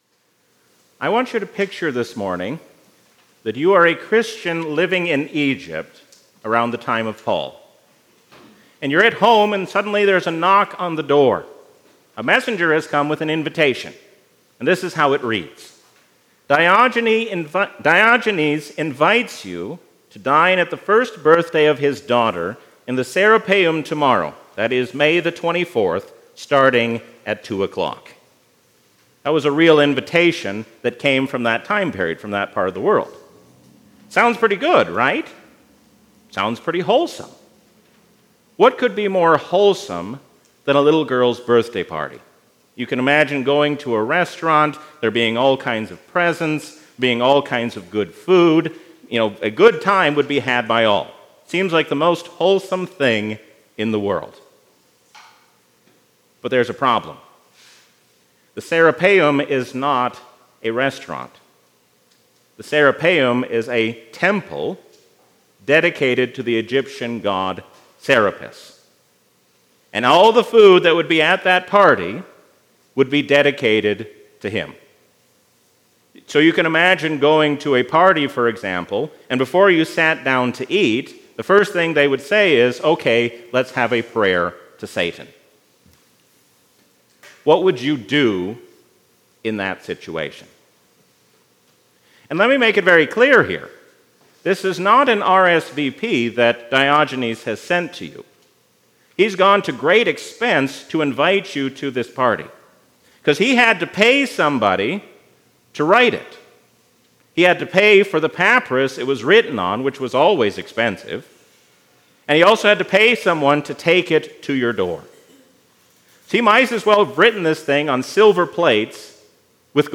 A sermon from the season "Easter 2024."